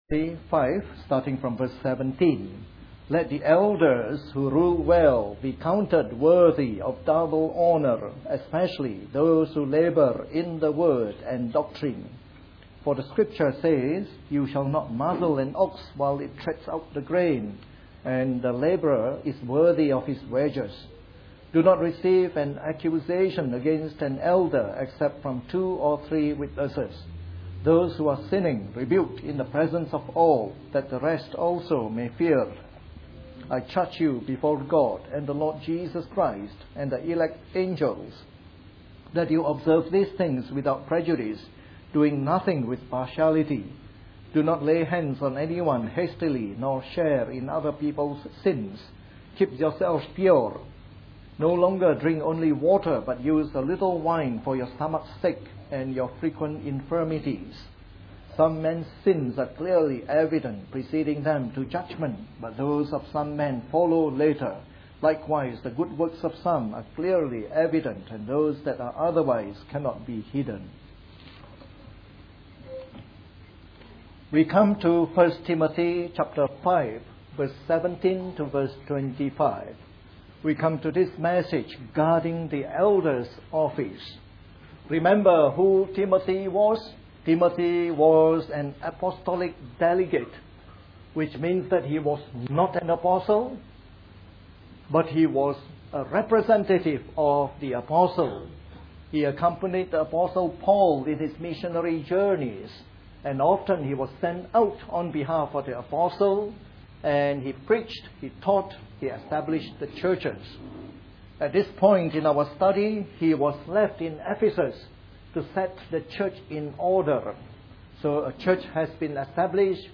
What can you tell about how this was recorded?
A sermon in the morning service from our series on 1 Timothy.